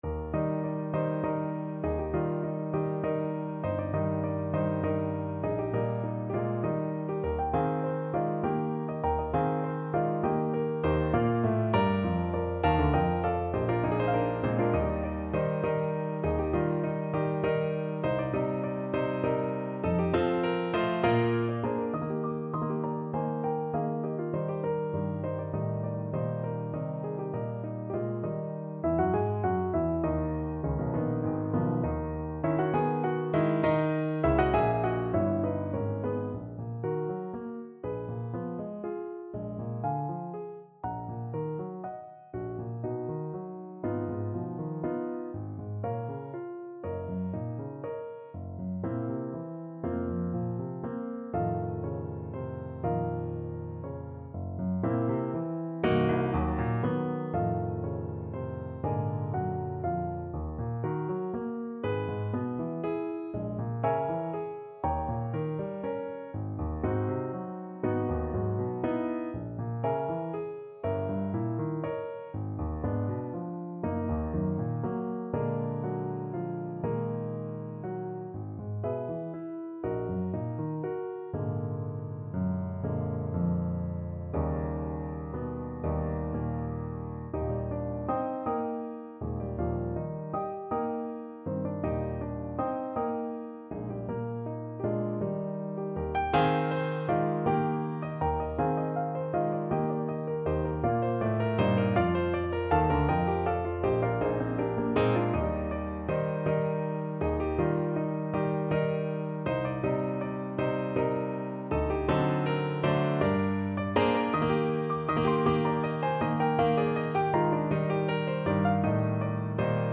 Piano version
No parts available for this pieces as it is for solo piano.
6/8 (View more 6/8 Music)
Piano  (View more Advanced Piano Music)
Classical (View more Classical Piano Music)